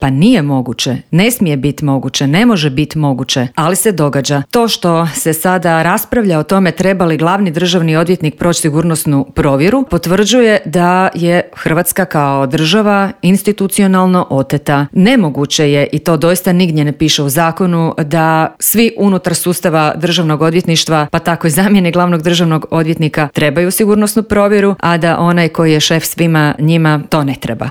ZAGREB - Prijepori oko Ivana Turudića i procedure izbora glavnog državnog odvjetnika, izmjene Kaznenog zakona, veliki prosvjed oporbe, sindikalni prosvjedi i potencijalni štrajkovi neke su od tema o kojima smo u Intervjuu Media servisa razgovarali sa saborskom zastupnicom iz Stranke s imenom i prezimenom Dalijom Orešković.